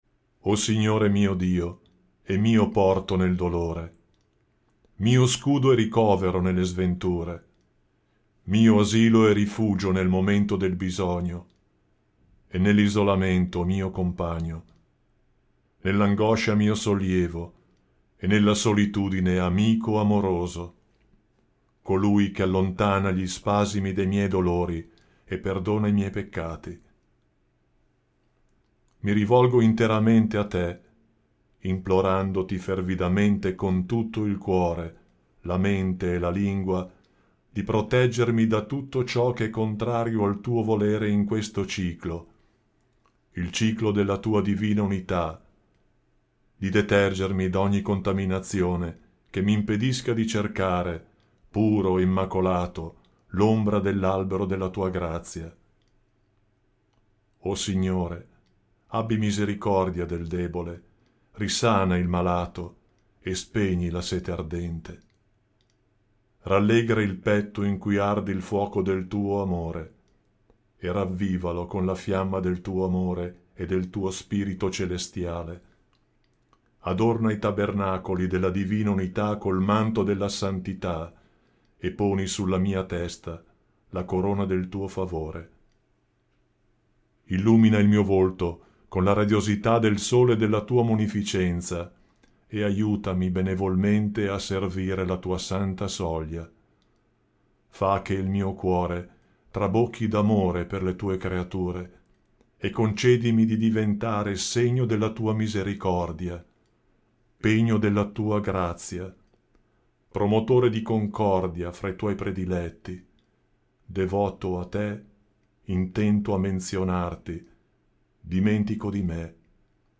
Audiolibri Bahá'í Gratis